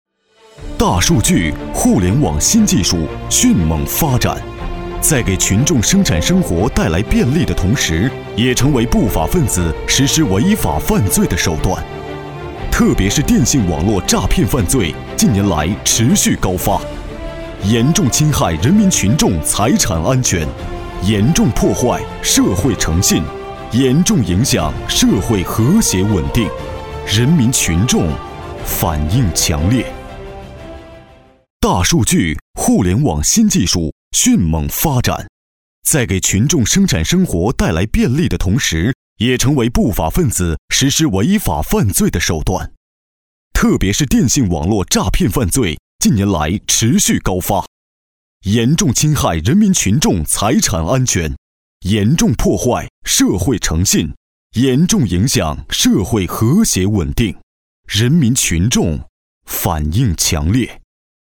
浑厚-样音试听-新声库配音网
23 男国406_专题_汇报_反电信网络诈骗中心工作纪实_浑厚 男国406
男国406_专题_汇报_反电信网络诈骗中心工作纪实_浑厚 .mp3